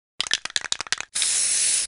Звуки баллончика с краской
На этой странице собраны звуки баллончика с краской, которые передают процесс создания граффити: от характерного стука шарика при встряхивании до равномерного шипения при распылении.
Звук спрея граффити в CS:GO